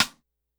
Perc_120.wav